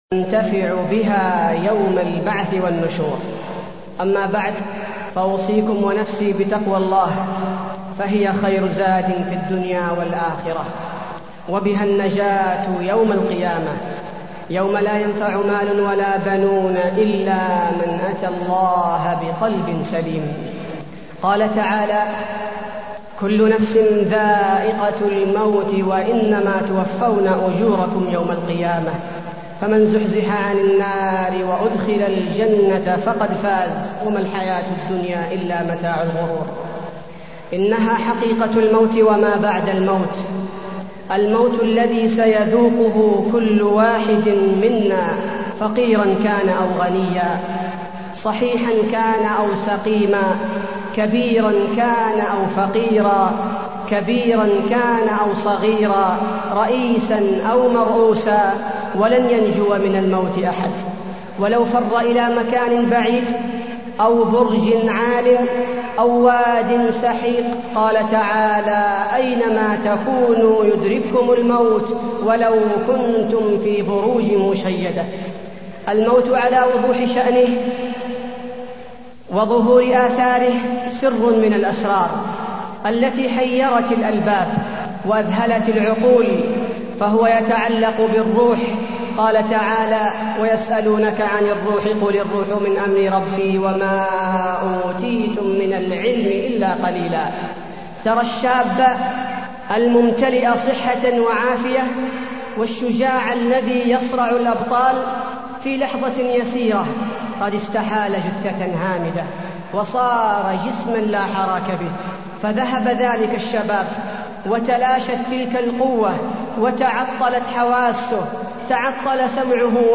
تاريخ النشر ١٩ محرم ١٤٢٢ هـ المكان: المسجد النبوي الشيخ: فضيلة الشيخ عبدالباري الثبيتي فضيلة الشيخ عبدالباري الثبيتي الموت